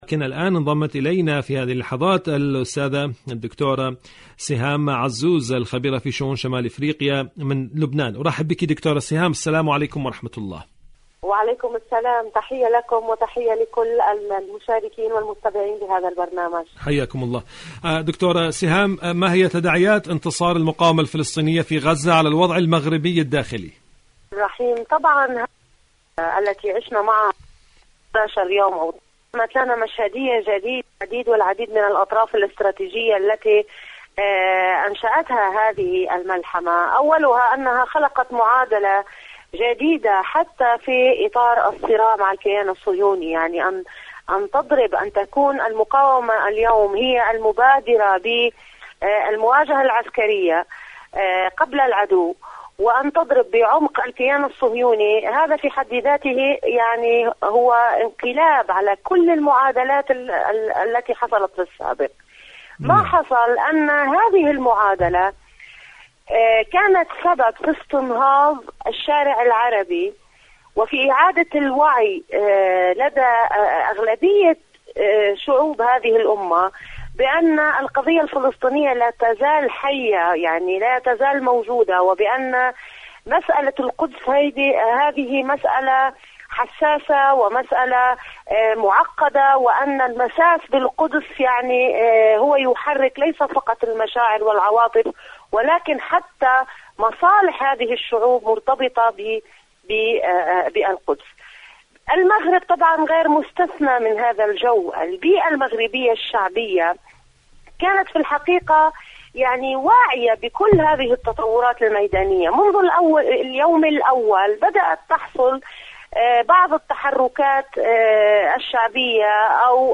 مشاركة هاتفية مشاركة صوتية